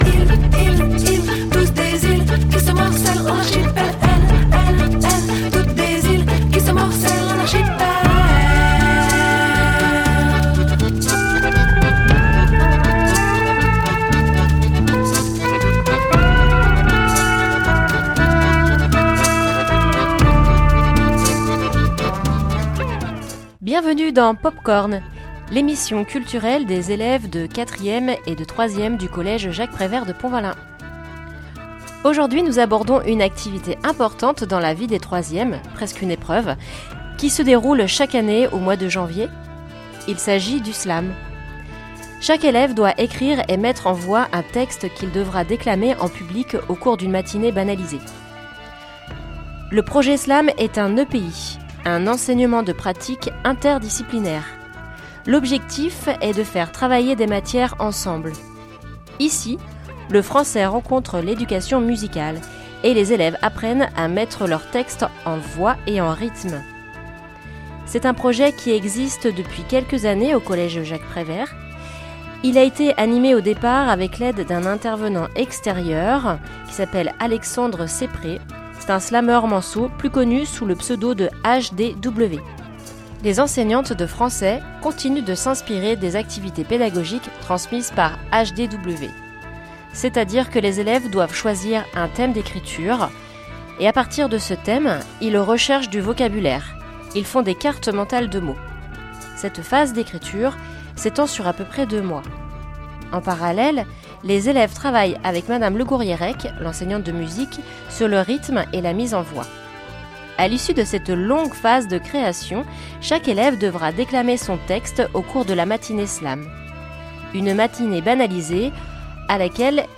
Slam émission complète.mp3